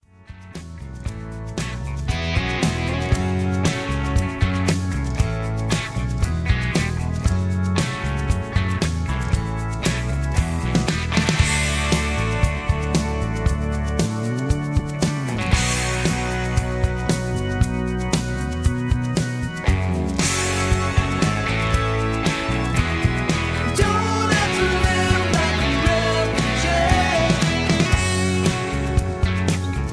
Key-F#m
Just Plain & Simply "GREAT MUSIC" (No Lyrics).